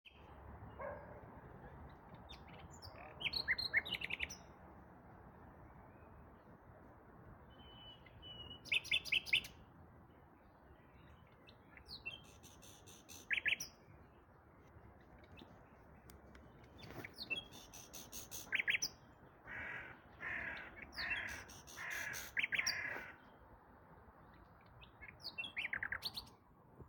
Die Nachtigall singt wie jeden Frühling lautstark und wunderschön in Berlin.
Nachtigallen in Berlin
Nachtigall.mp3